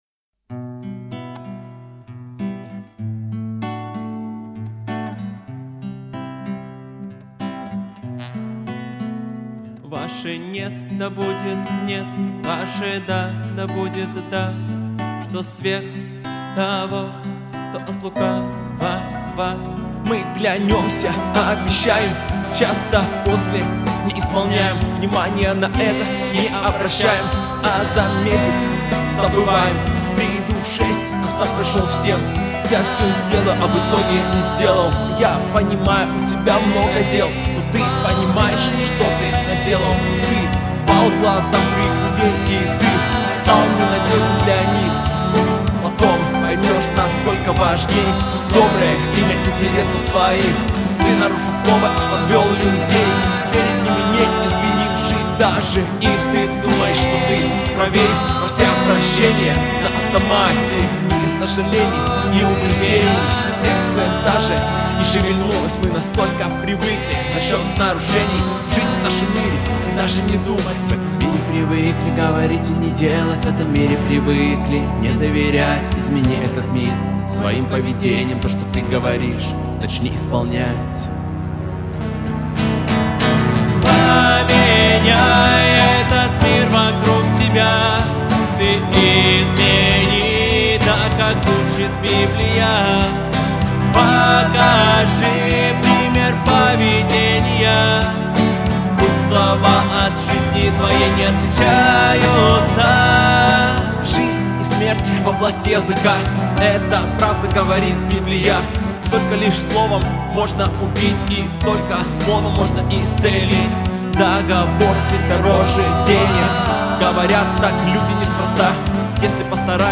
песня
382 просмотра 95 прослушиваний 4 скачивания BPM: 95